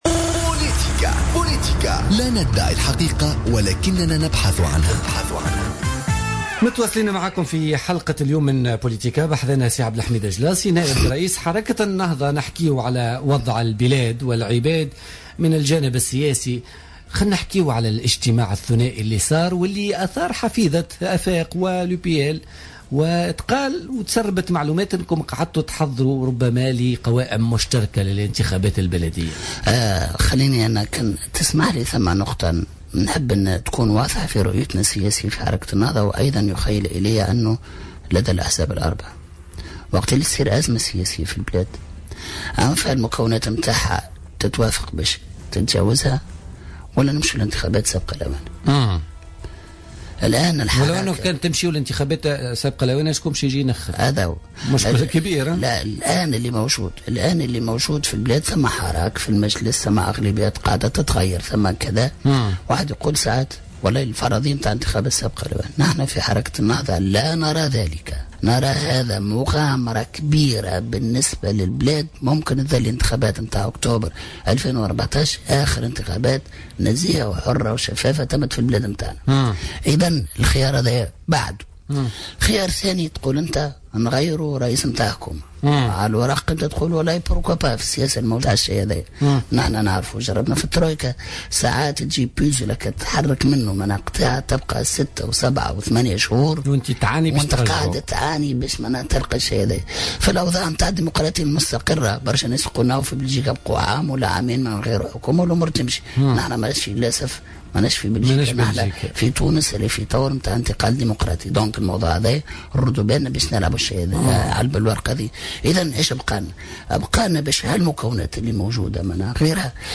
أكد نائب رئيس حركة النهضة عبد الحميد الجلاصي ضيف بوليتيكا اليوم الأربعاء 27 أفريل 2016 أن البلاد تعيش اليوم حالة حراك على مستوى المجلس خاصة في ظل تغير الاغلبيات واعادة تشكلها.